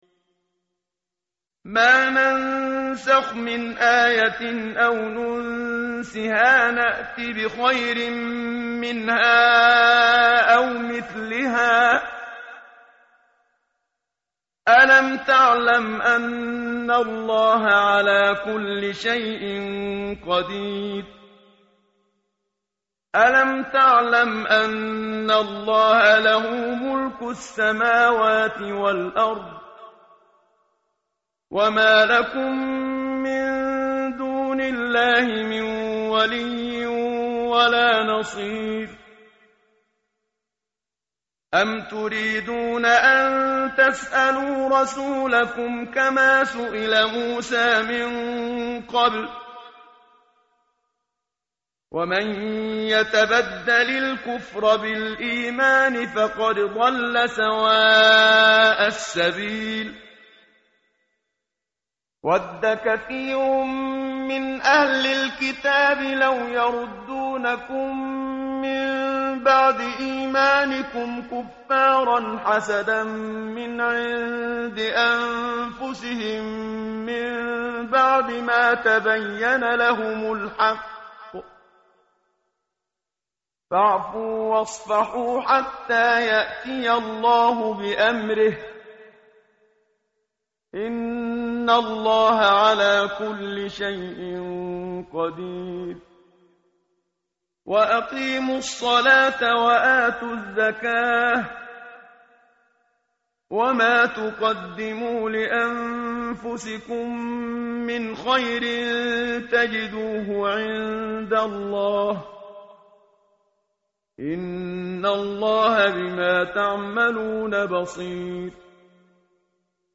tartil_menshavi_page_017.mp3